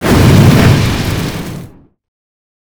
sk05_shot.wav